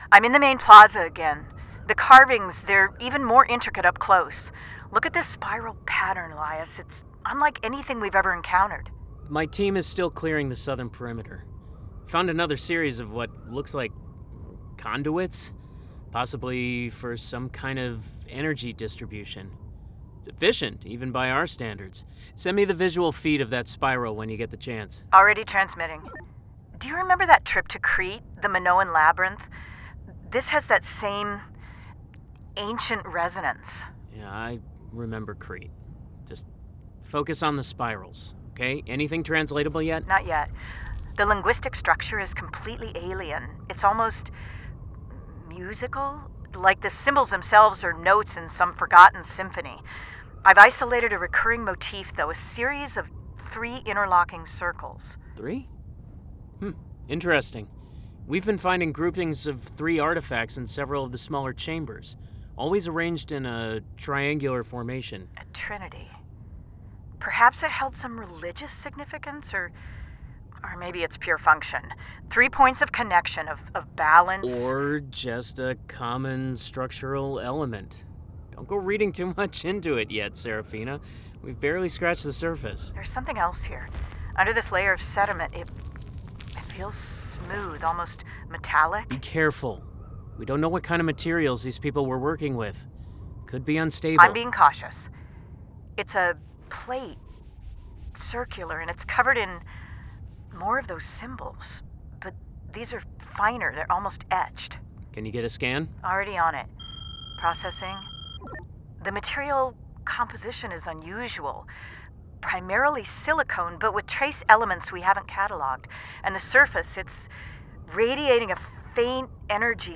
Drama 1
English - Midwestern U.S. English